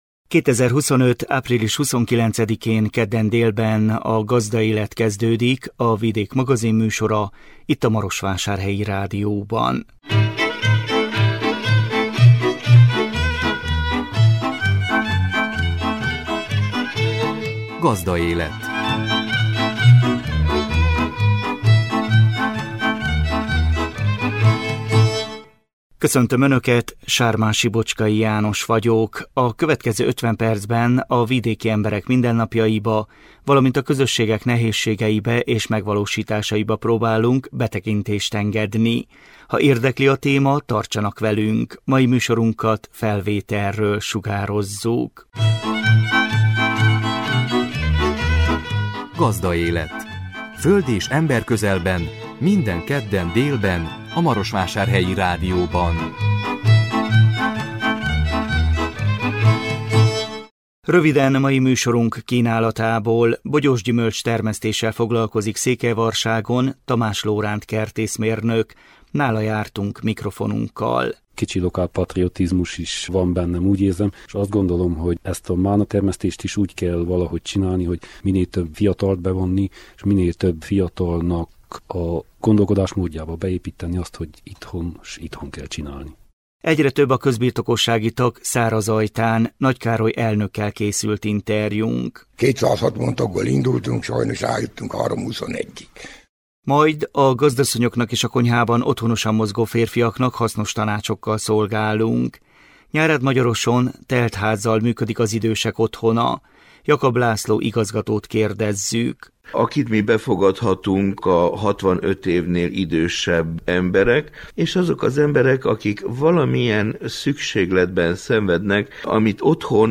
Nála jártunk mikrofonunkkal. Egyre több a közbirtokossági tag Szárazajtán.